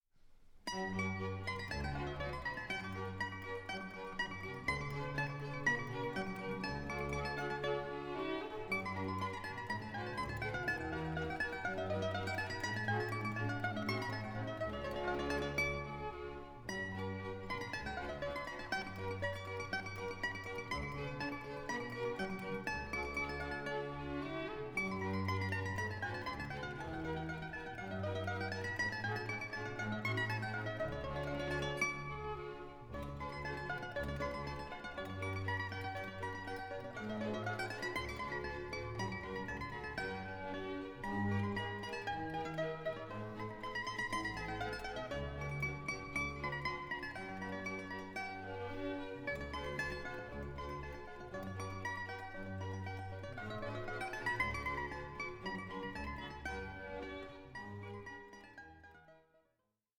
works for mandolin and orchestra